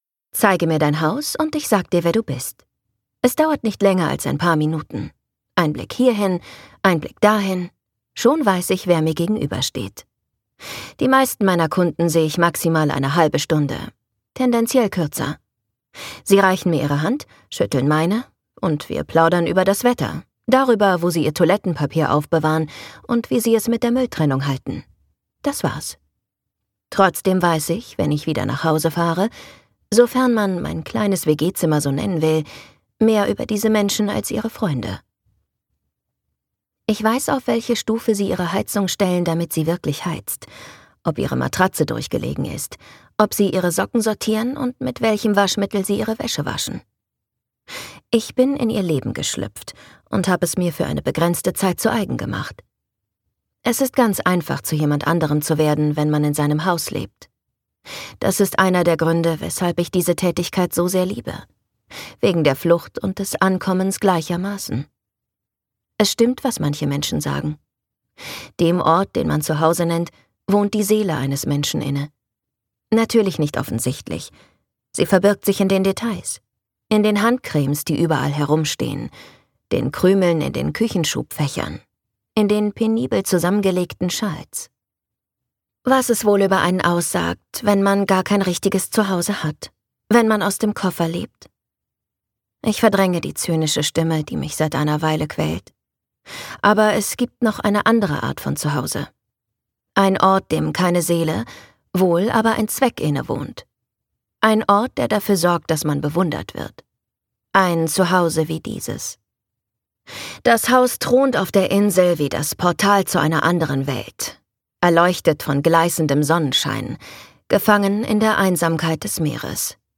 Das neue aufregende Hörbuch der Bestsellerautorin
Gekürzt Autorisierte, d.h. von Autor:innen und / oder Verlagen freigegebene, bearbeitete Fassung.